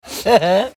Holly Willoughby Laugh